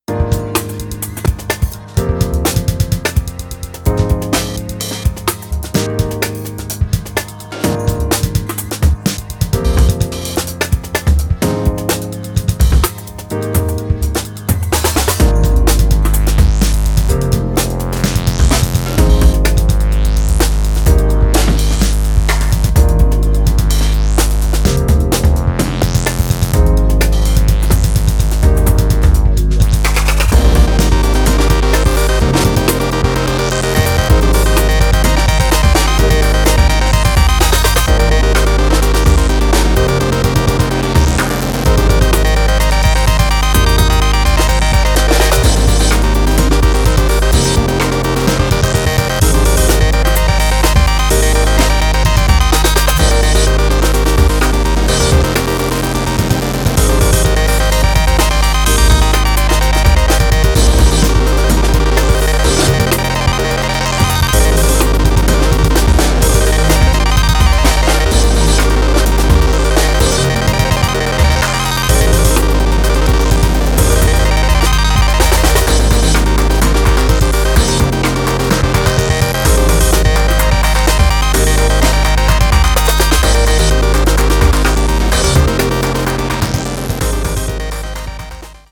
Electronix Bass Breaks